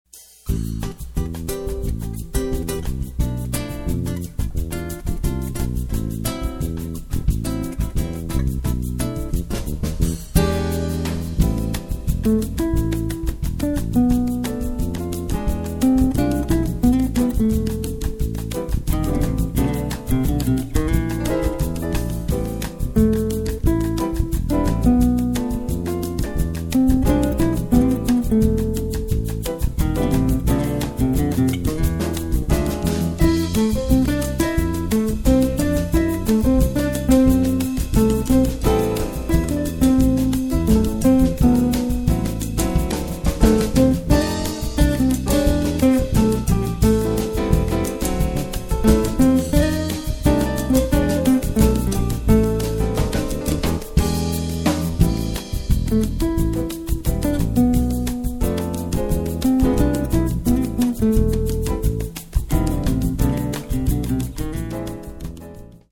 Idéal pour des soirées à saveur latine où l'ambiance doit être chaleureuse et festive.
Détail combo: guitare, piano, basse et batterie (percussion en option).